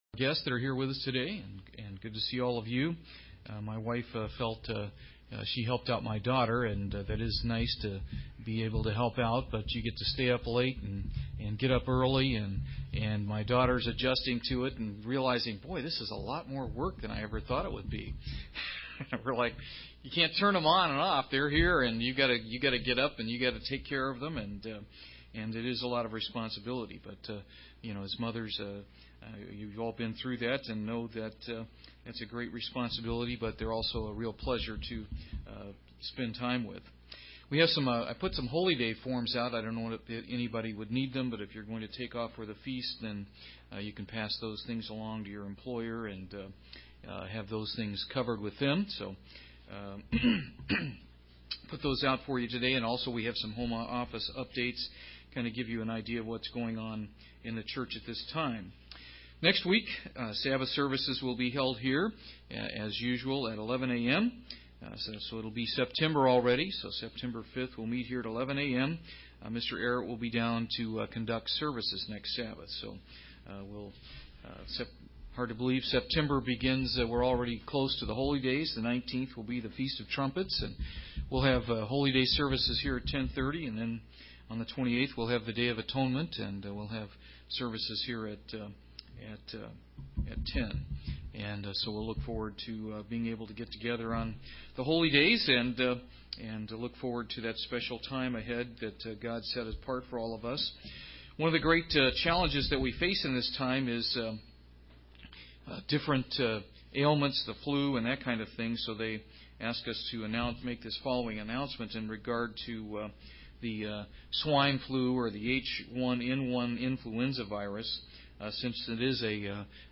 Given in Paintsville, KY
UCG Sermon Studying the bible?